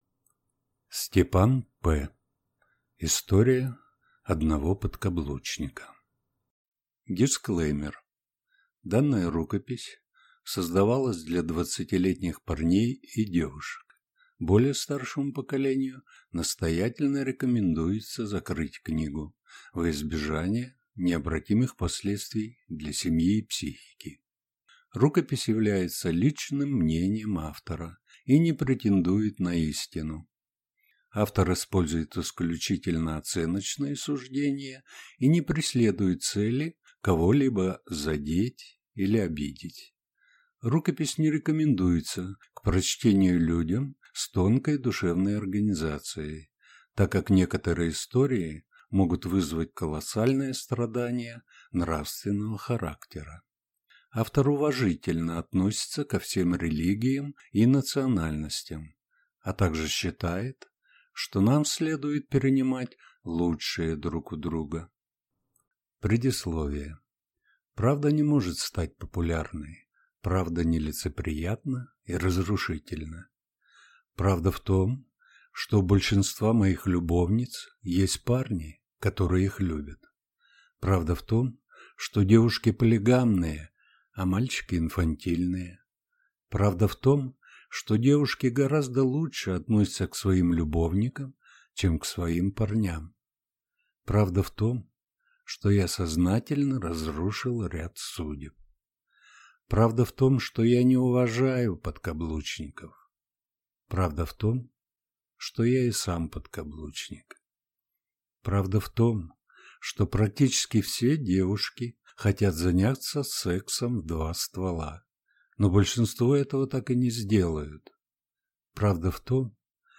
Аудиокнига История одного подкаблучника | Библиотека аудиокниг